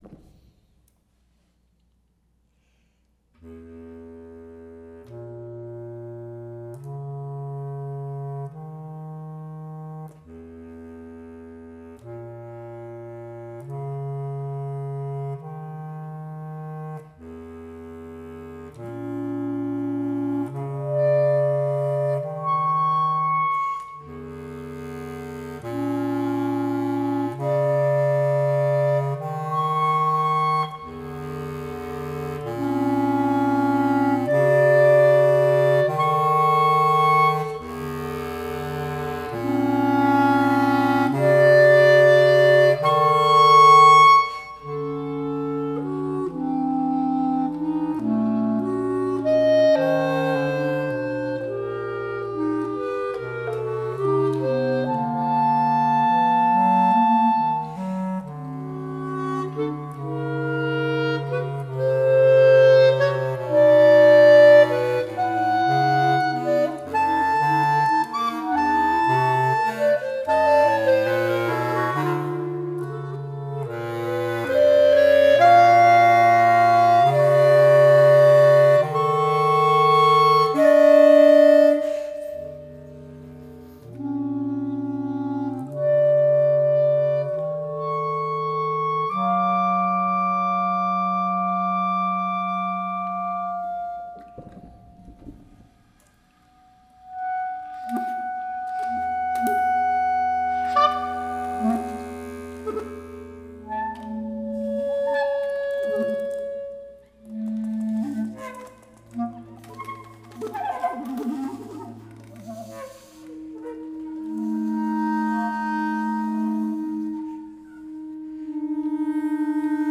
for clarinet trio (2 Bb clari/1 Bs clari)